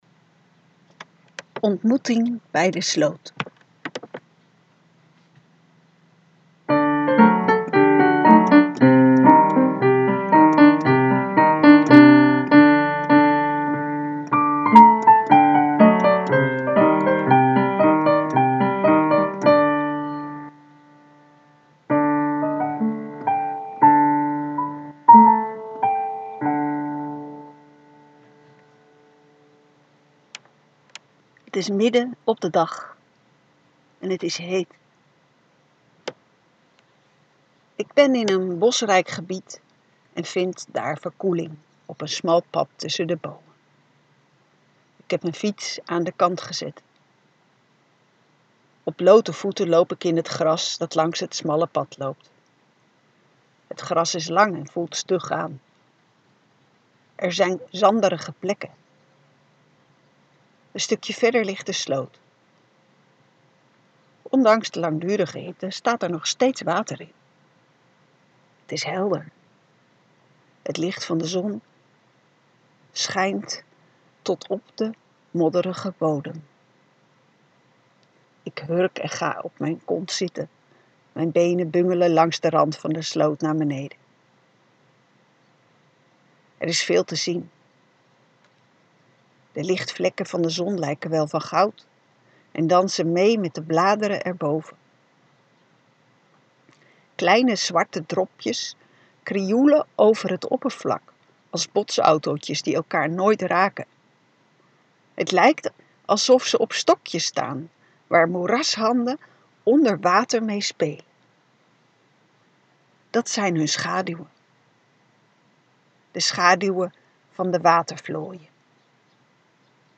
ontmoeting-bij-de-sloot.mp3